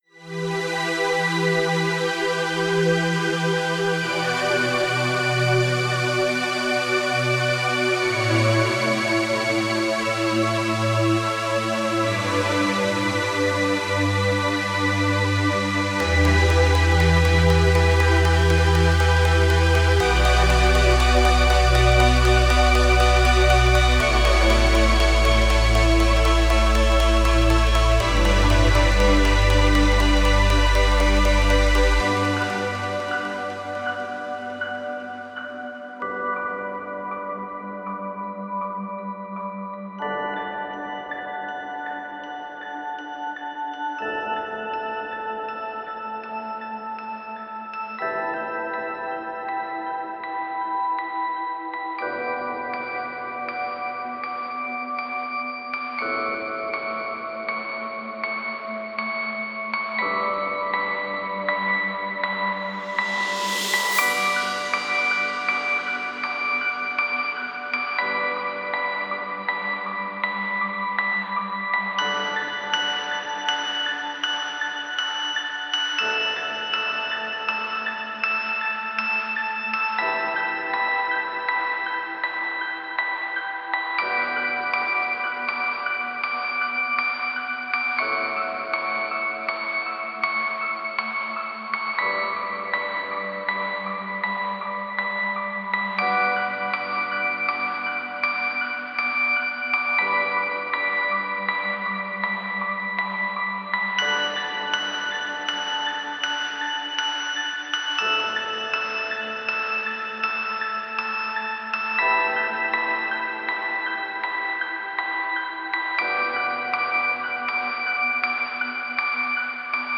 Ambient Chill Out / Lounge Multi-genre